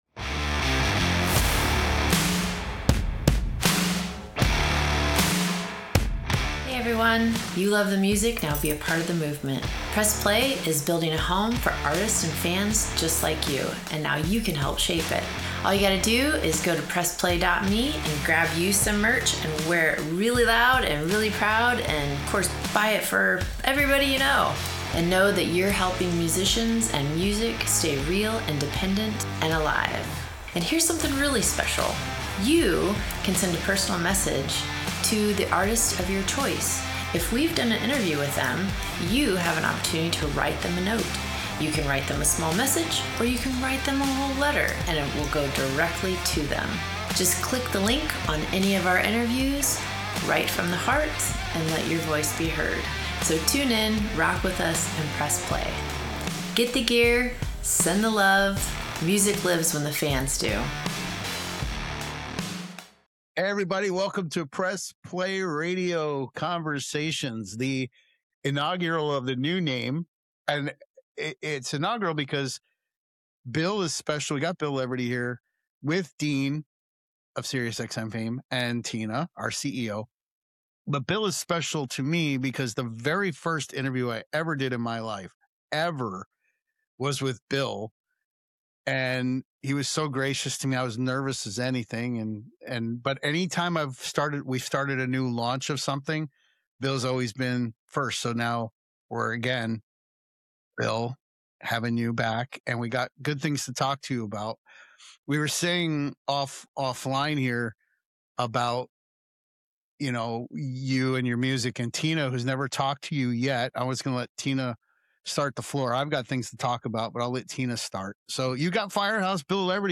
Firehouse guitarist and songwriter Bill Leverty joins Press Play Radio Conversations for a wide-ranging, deeply human discussion on ballads, guitar tone, imperfection in music, and what still matters after decades on the road.